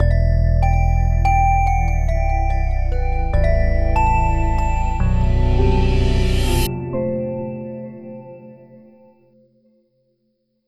Windows NT 7.0 Embedded Startup.wav